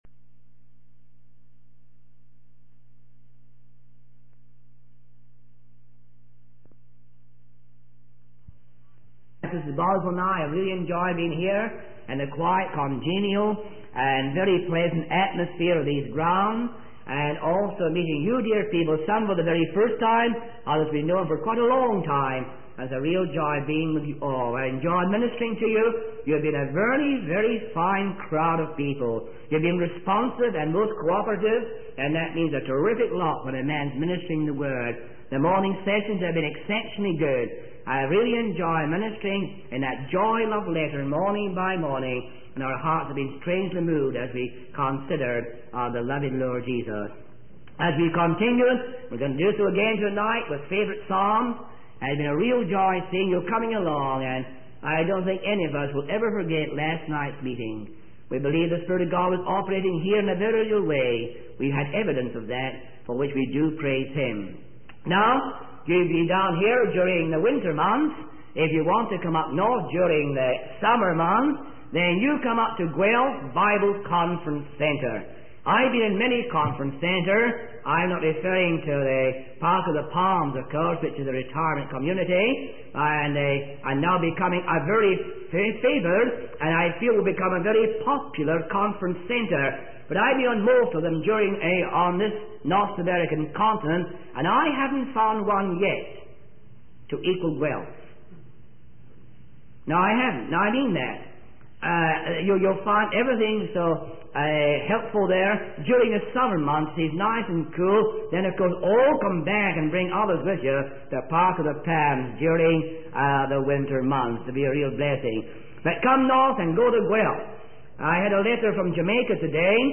In this sermon, the speaker emphasizes the importance of keeping our focus on Jesus and his ability to see in the dark. He uses the example of Peter walking on water and how Jesus came to the disciples in the midst of a storm. The speaker also highlights the need for Christians to be filled with the love of Jesus in order to overcome spiritual stagnation.